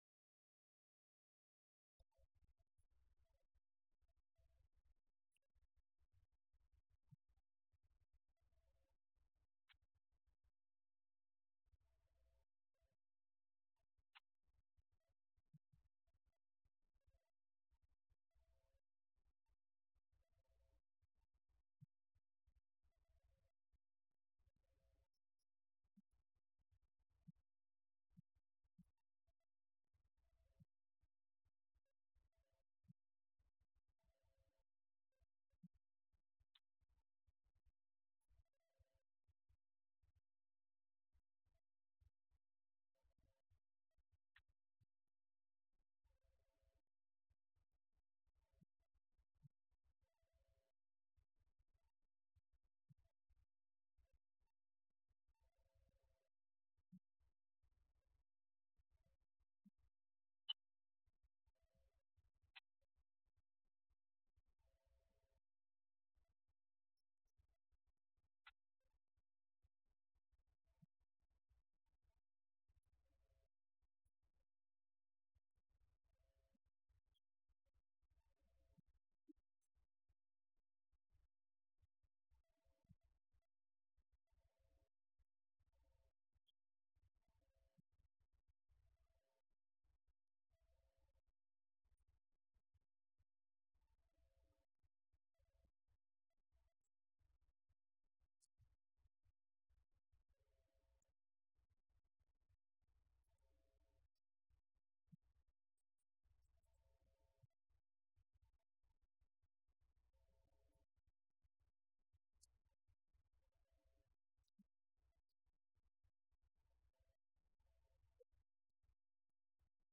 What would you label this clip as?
Series: Schertz Lectureship